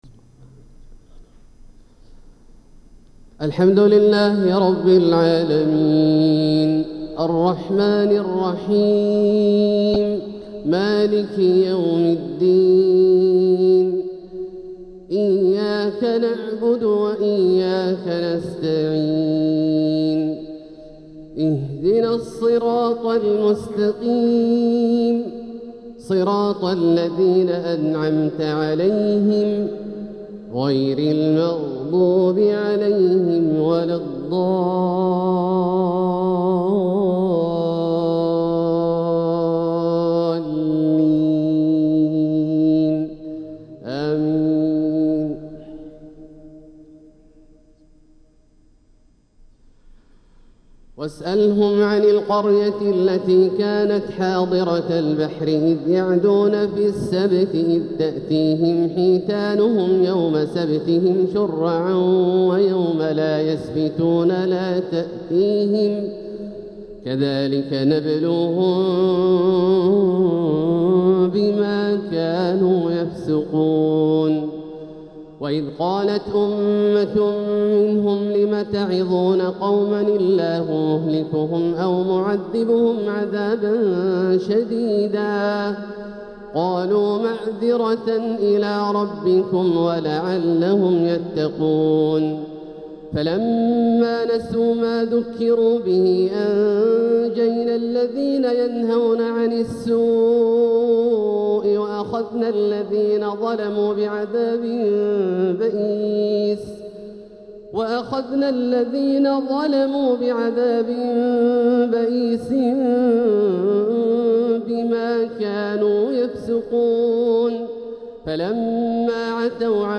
تلاوة من سورة الأعراف 163-178 | فجر الاثنين 2 ربيع الأول 1447هـ > ١٤٤٧هـ > الفروض - تلاوات عبدالله الجهني